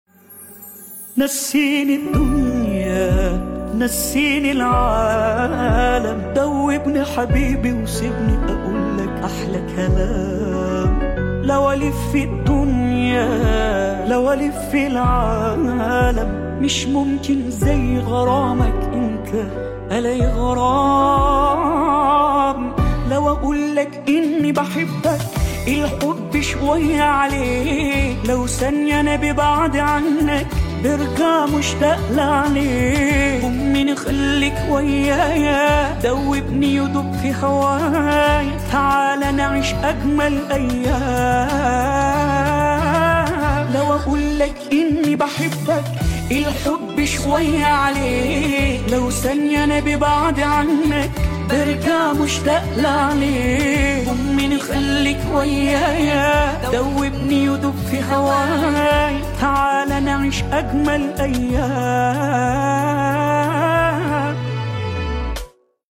خواننده جوان و خوش صدای پاپ ایرانی